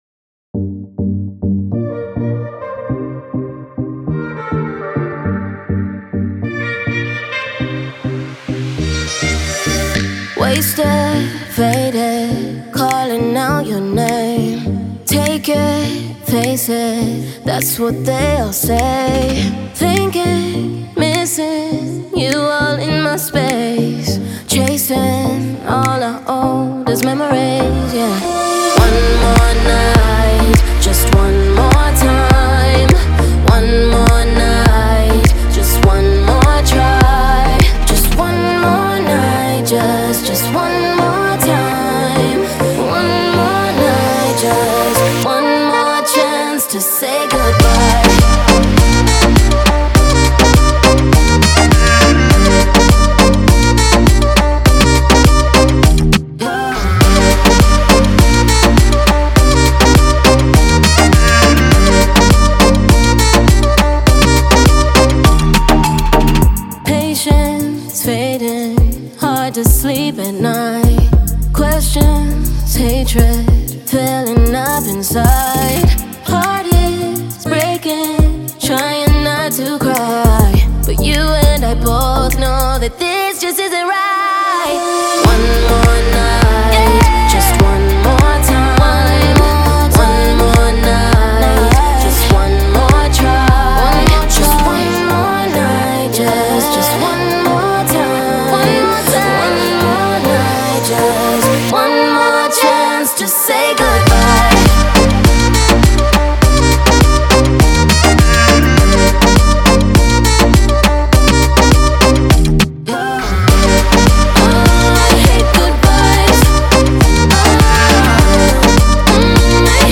это зажигательная композиция в жанре EDM